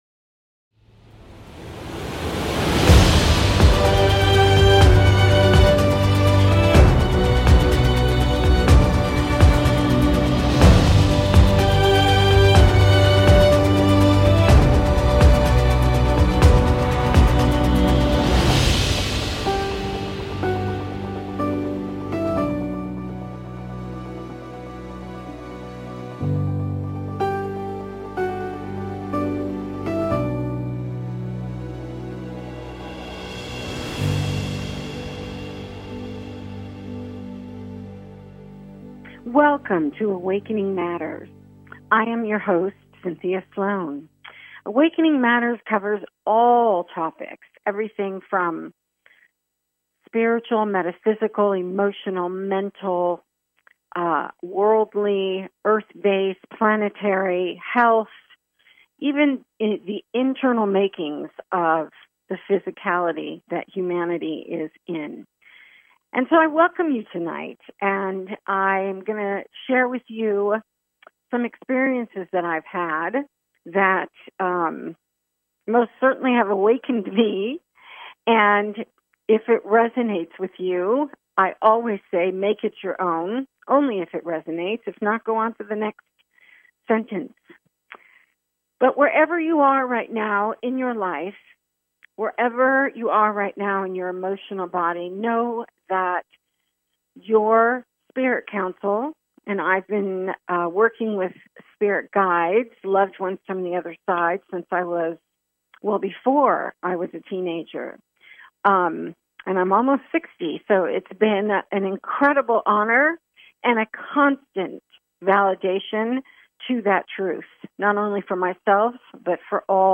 A spiritual dialogue that invites divine wisdom, joy and laughter.